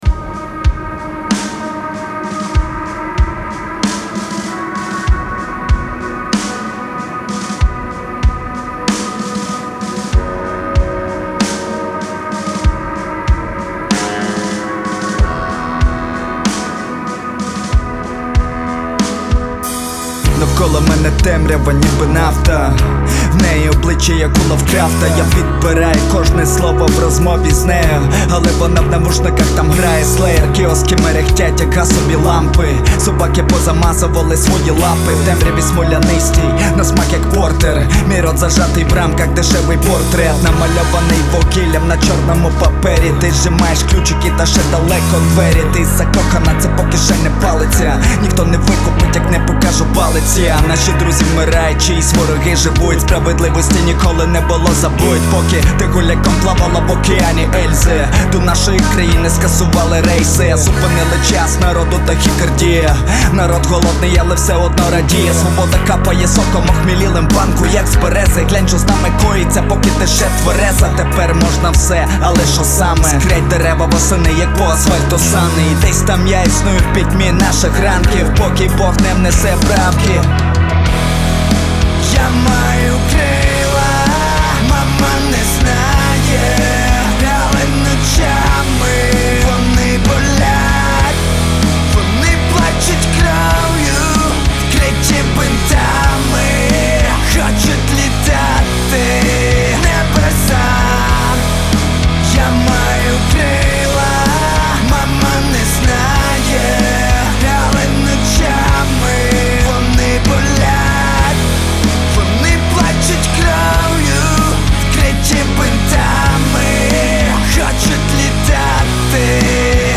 • Жанр: Rock, Indie